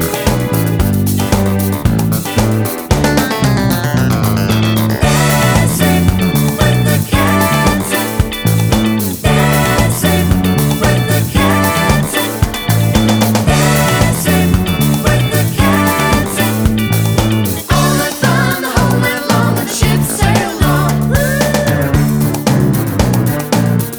With FX at End Pop